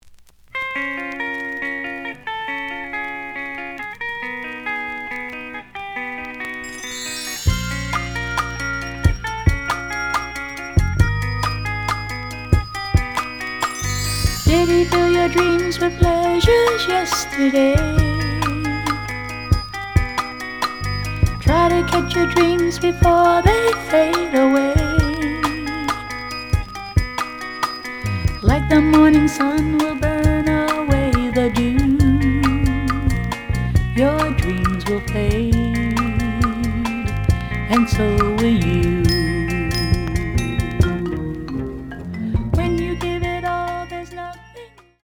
The audio sample is recorded from the actual item.
●Genre: Soul, 70's Soul
Looks good, but slight noise on A side.)